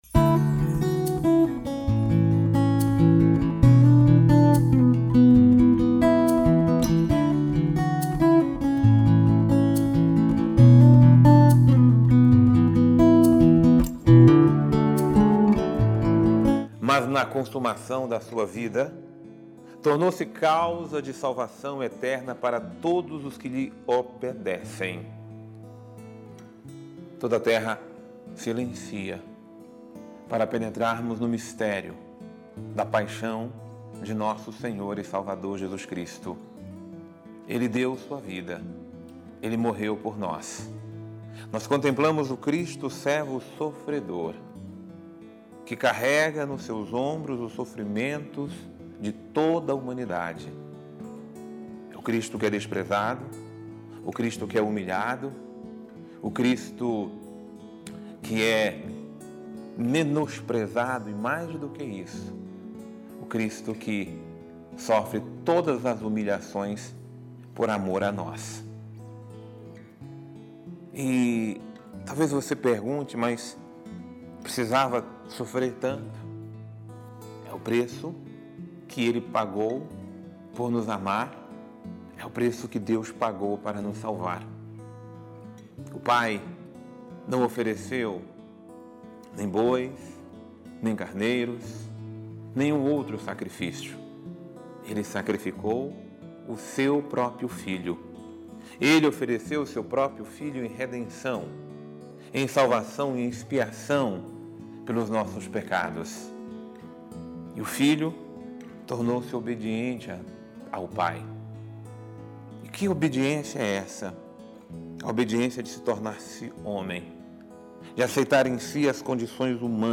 Homilia diária | A Paixão de Cristo nos concede vida nova